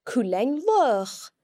The non-slender L can also be heard in cuilean (a puppy):